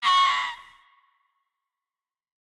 1.21.5 / assets / minecraft / sounds / mob / fox / screech2.ogg
screech2.ogg